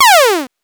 8 bits Elements / laser shot
laser_shot_2.wav